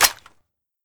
Index of /server/sound/weapons/svt40
g3sg1_cliptap.mp3